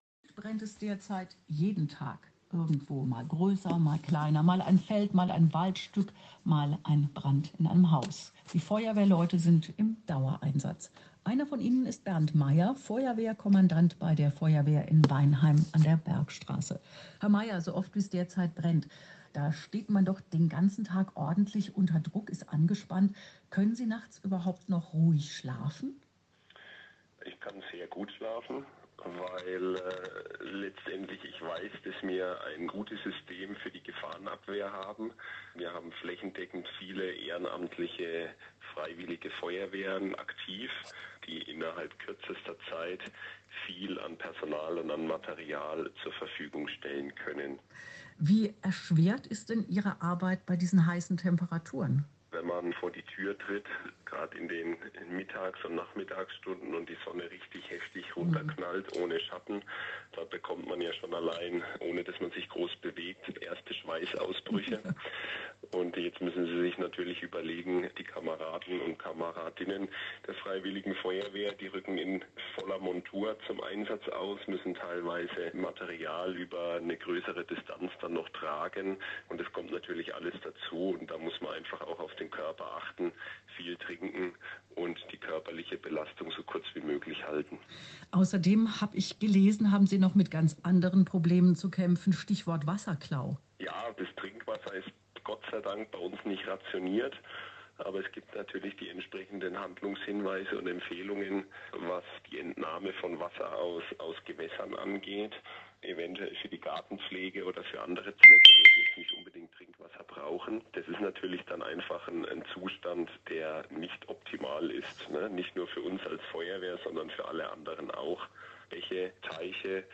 Feuerwehrleute im Dauereinsatz – Im Interview mit dem SWR